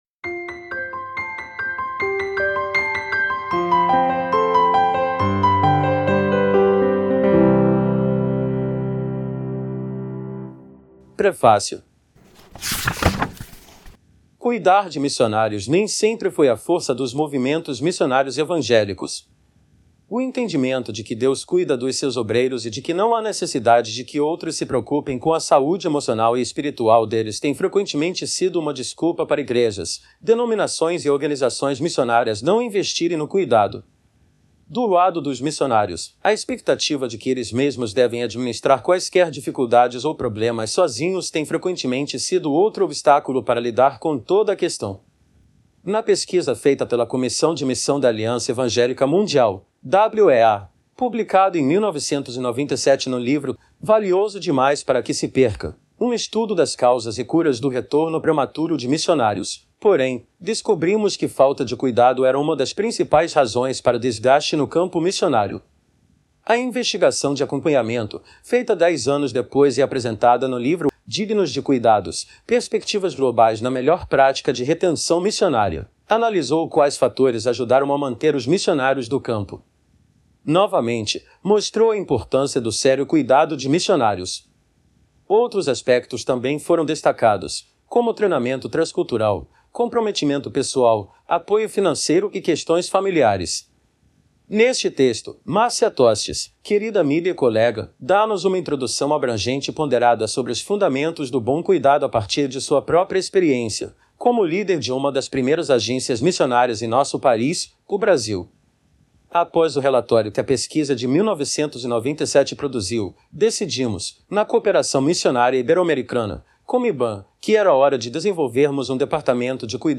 02-Prefacio.mp3